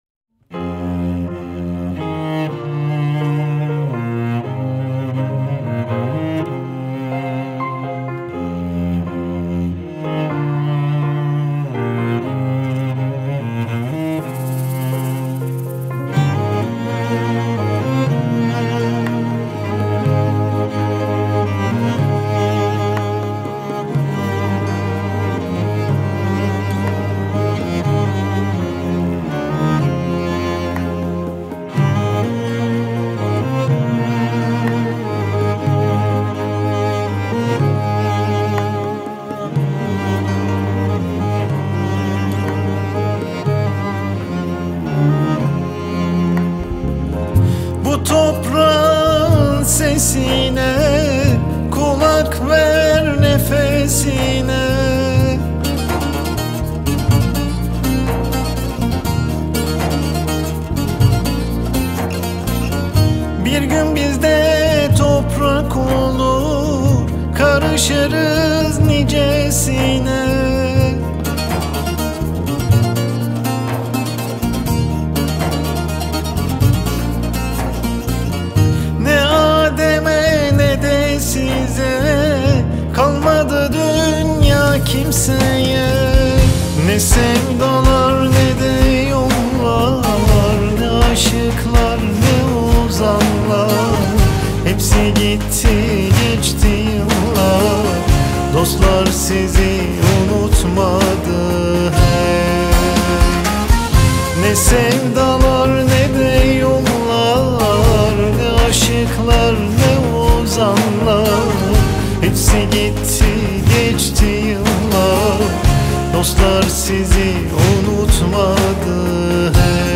• Категория: Турецкие песни